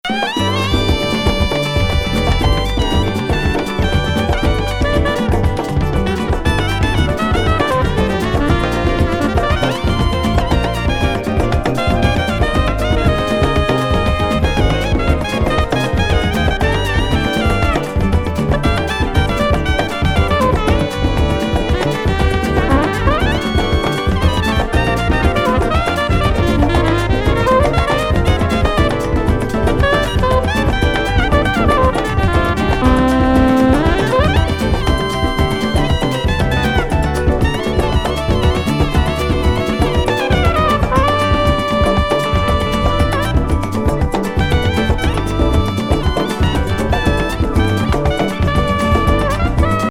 気持ちよすぎ電子グルーブ